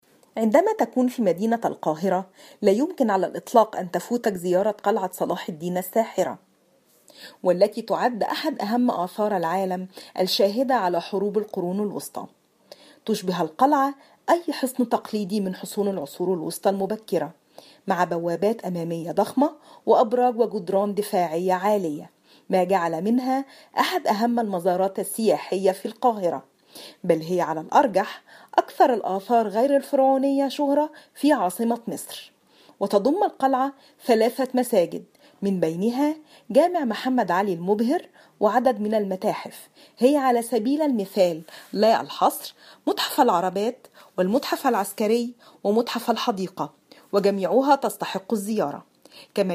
Arabic Egyptian female Vice-over and translator of German and Arabic